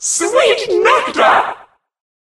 bea_mon_kill_vo_03.ogg